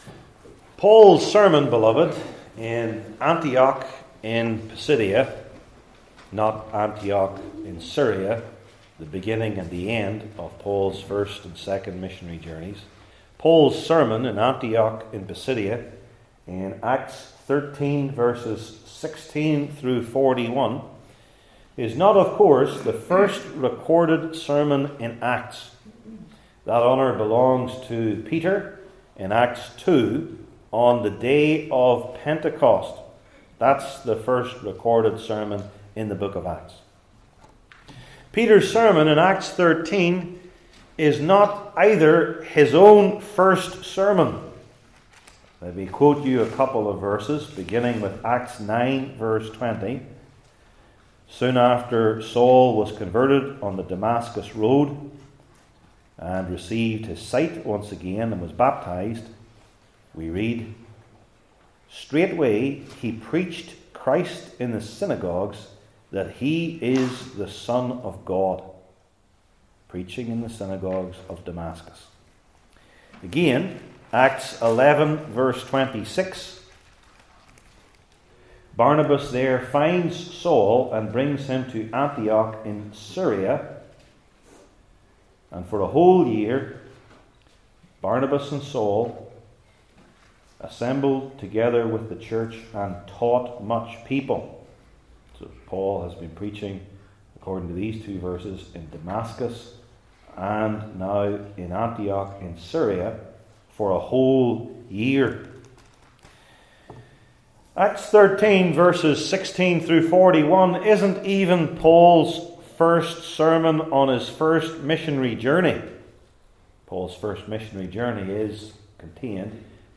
New Testament Individual Sermons